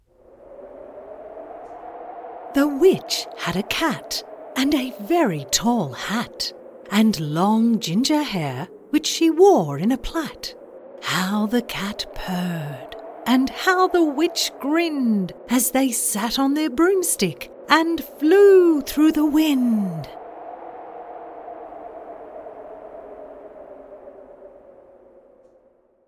Englisch (Australisch)
Erzählung
E-Learning